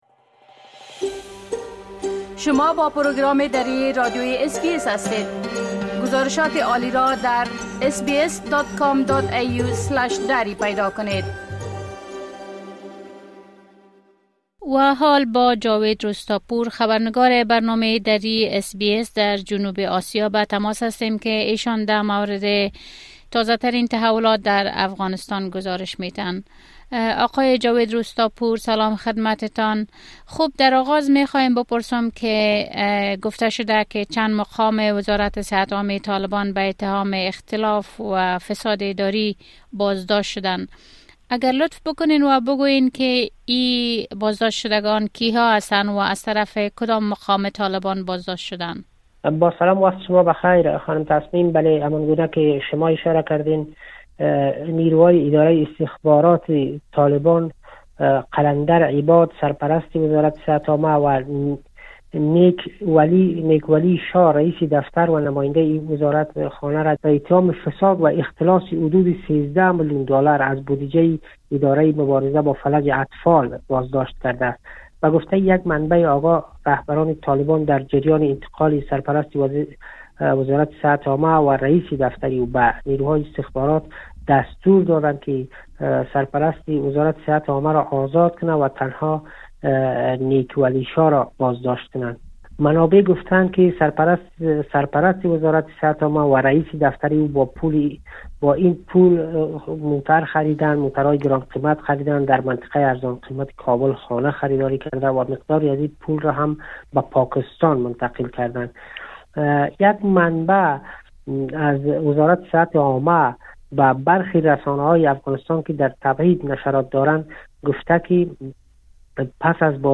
گزارش كامل خبرنگار محلی ما از اوضاع امنيتى و تحولات مهم ديگر در افغانستان را اين‌جا بشنوید.